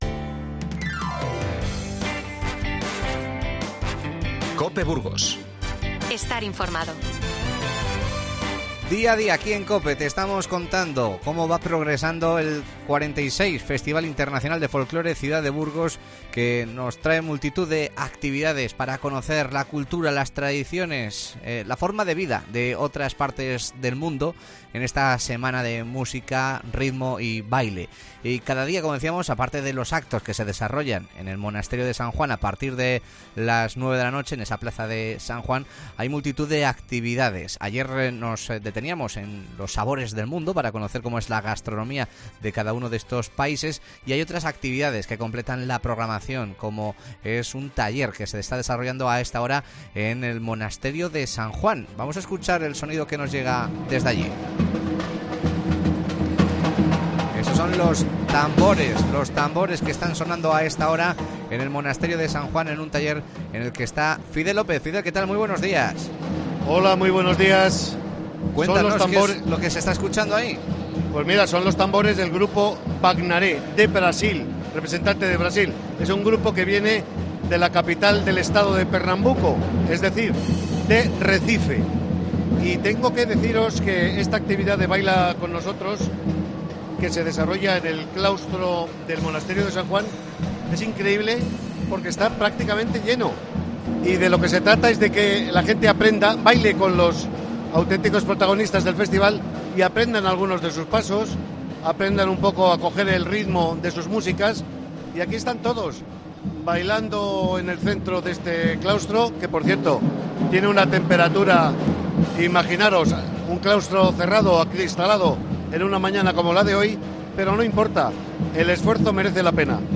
Nos vamos en directo hasta el Monasterio de San Juan, donde el grupo de Brasil ofrece su arte a los burgaleses que participan en el Festival Internacional de Folclore.